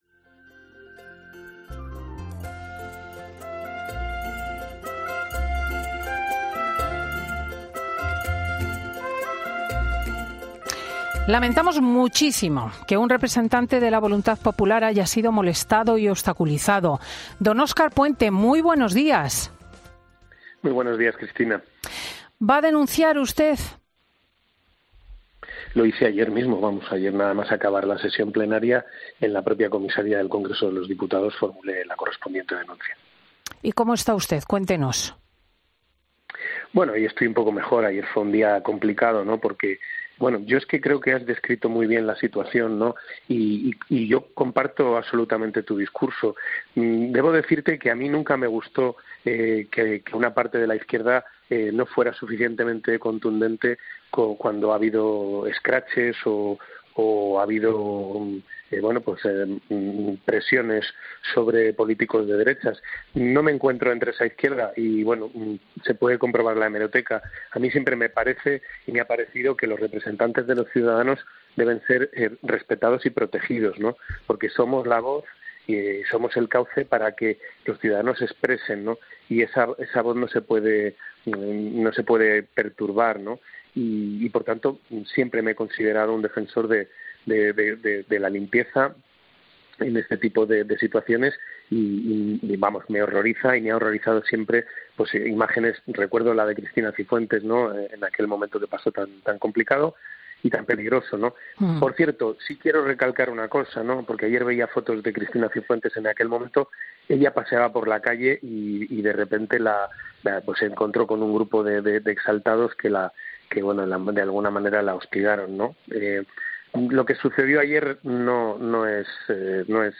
El diputado y exalcalde vallisoletano ha pasado por 'Fin de Semana' con Cristina López Schlichting para hablar sobre la posible investidura de Pedro...